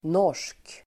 Uttal: [når_s:k]